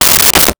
Toilet Paper Dispenser 04
Toilet Paper Dispenser 04.wav